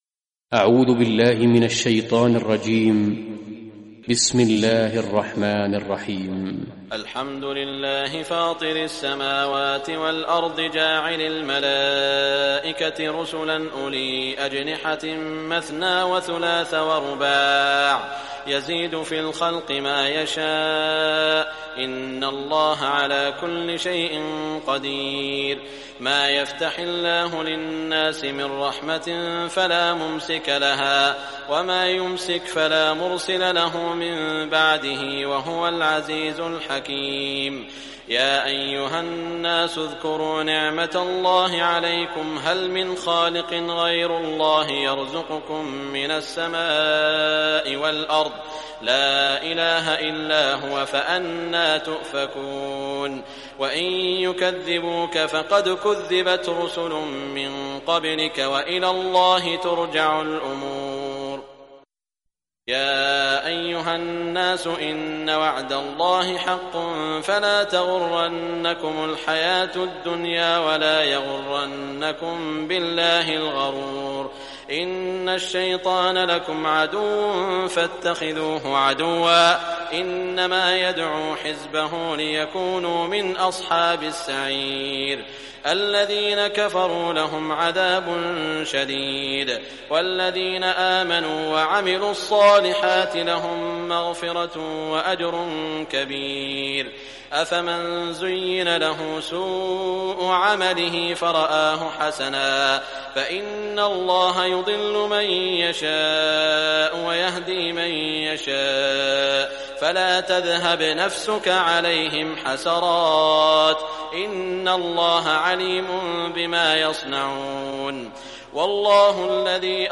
Surah Fatir Recitation by Sheikh Shuraim
Surah Fatir, listen or play online mp3 tilawat / recitation in Arabic in the beautiful voice of Imam e Kaaba Sheikh Saud al Shuraim.